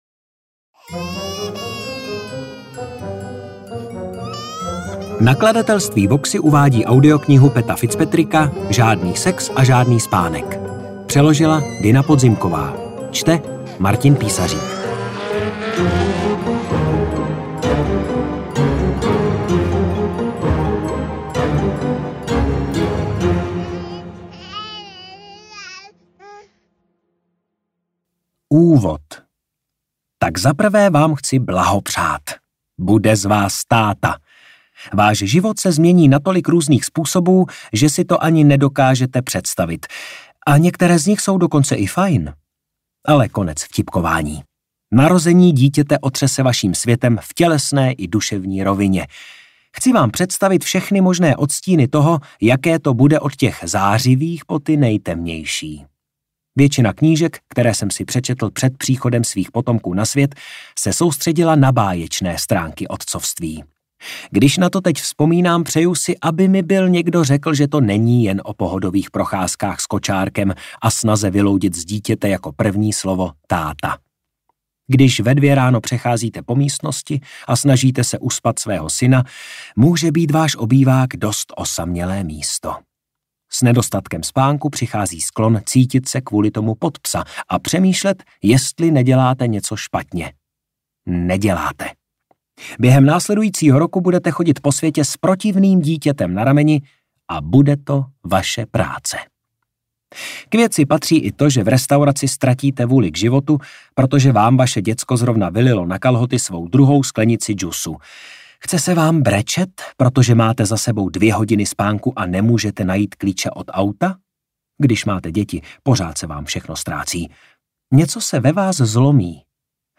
AudioKniha ke stažení, 84 x mp3, délka 13 hod., velikost 709,9 MB, česky